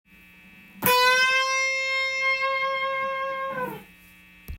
１弦７フレットをハーフチョーキング
ハーフチョーキングすることで半音隣のＣの音に変化するので